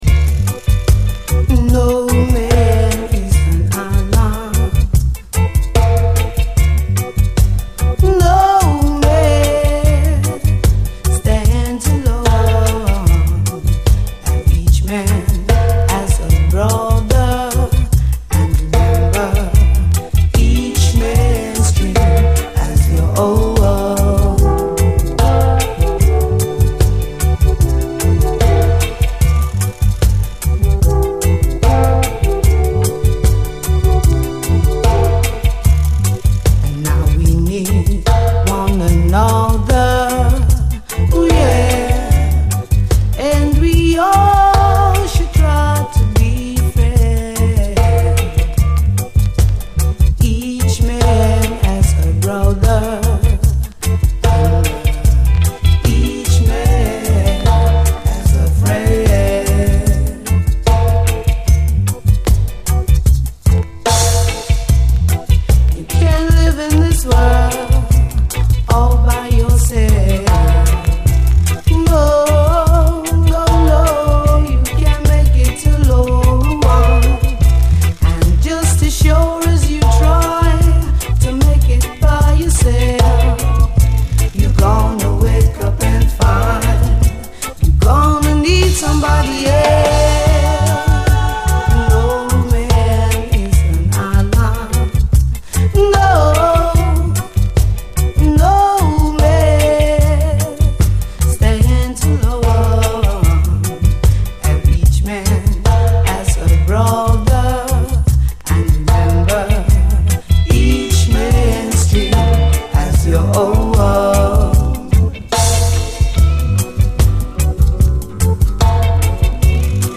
REGGAE
間奏のアコギも本当にヘヴンリー。